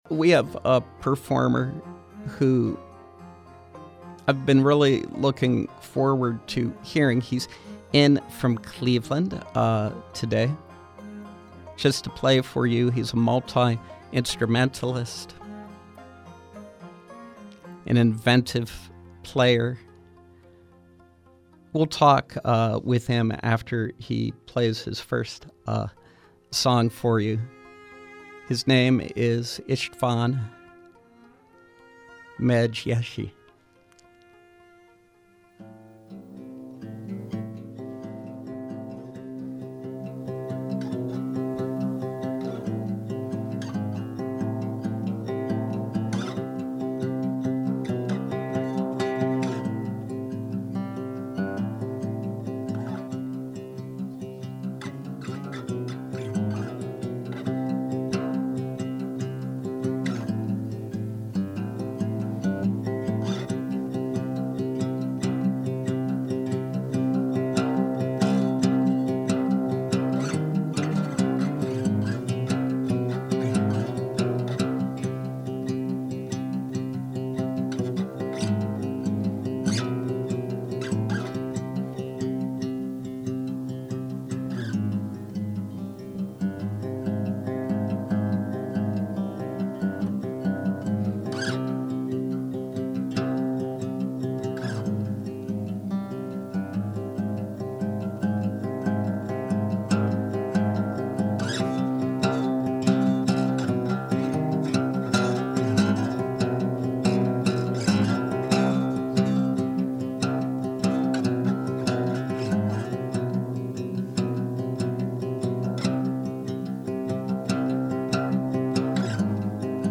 Cleveland-based multi-instrumentalist